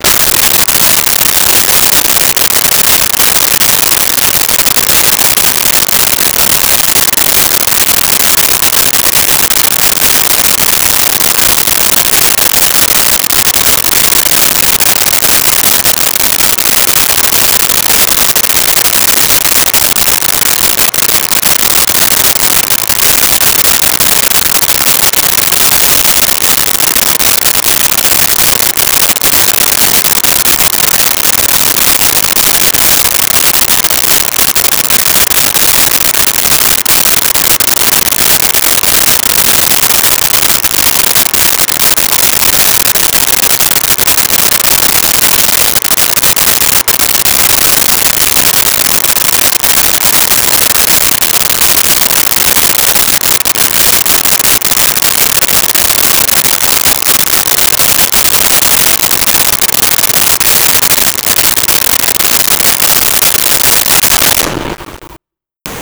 Projector 16MM Sequence
Projector 16MM Sequence.wav